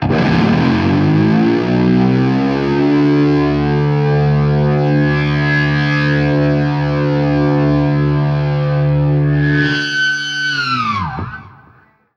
DIVEBOMB 1-R.wav